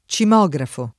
cimografo [ © im 0g rafo ]